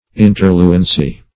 Search Result for " interluency" : The Collaborative International Dictionary of English v.0.48: Interluency \In"ter*lu`en*cy\, n. [L. interluens, p. pr. of interluere to flow between; inter + luere.] A flowing between; intervening water.